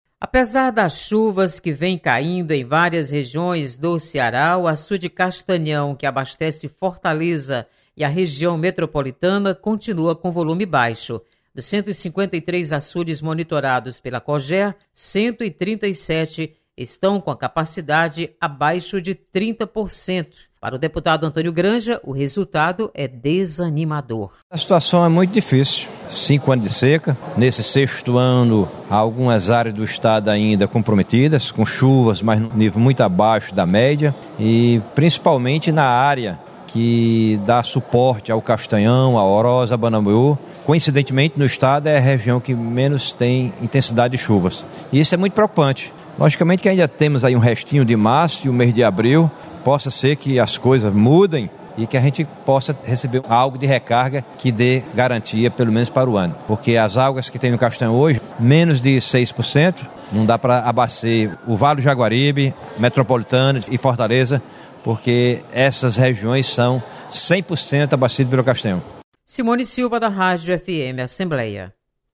Deputado Antônio Granja mostra preocupação com volume de água no Castanhão. Repórter